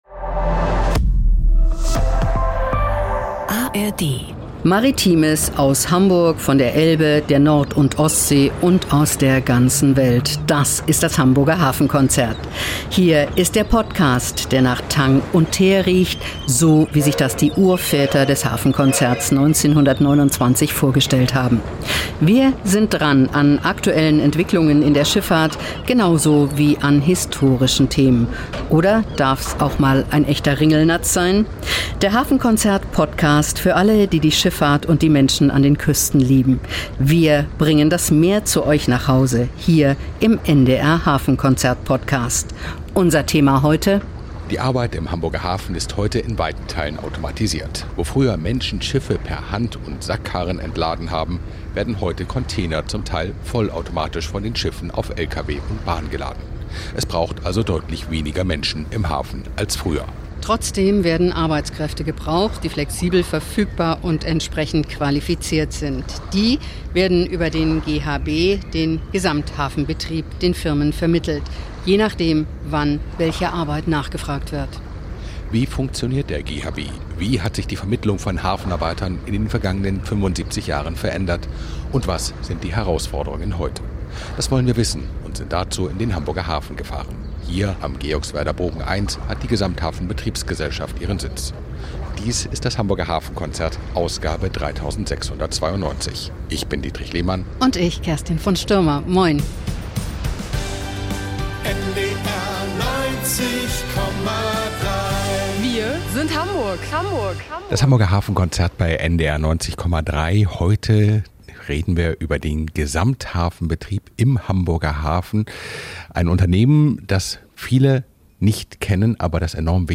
Historische Hörspiel-Szenen geben Einblick in die Arbeitskämpfe früherer Jahrzehnte.